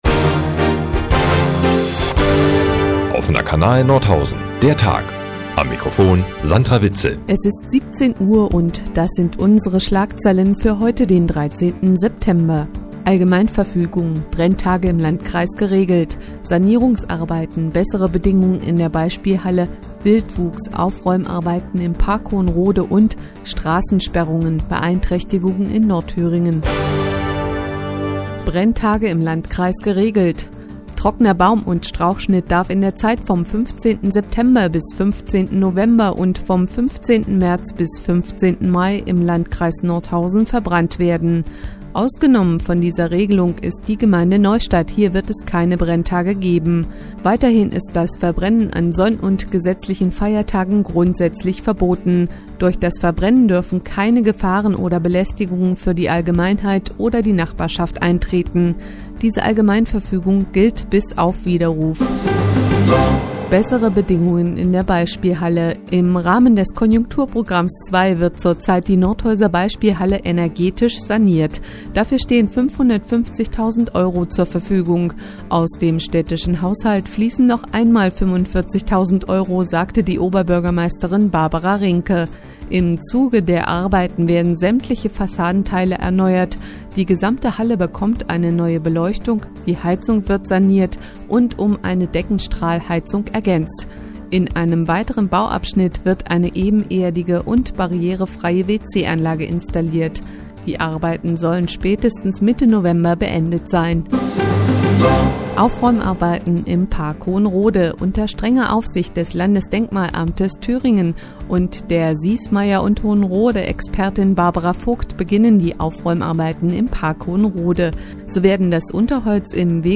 Die tägliche Nachrichtensendung des OKN ist nun in der nnz zu hören.